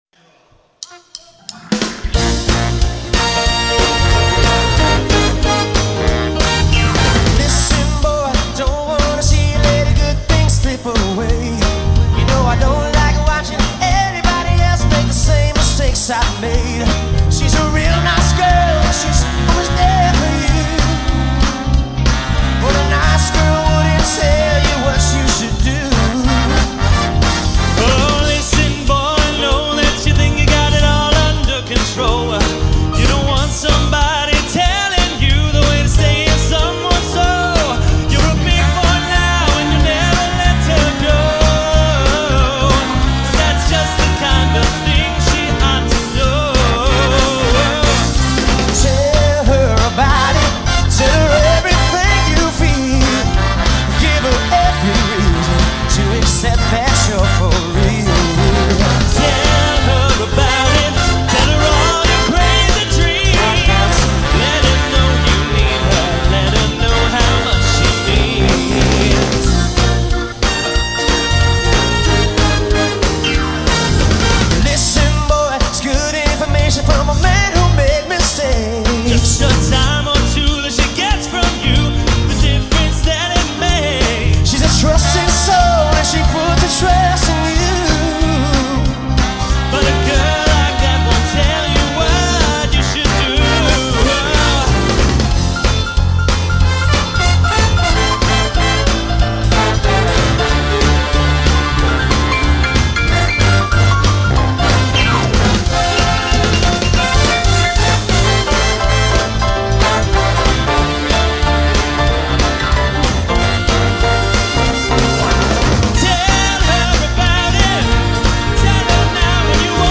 featuring the dueling pianists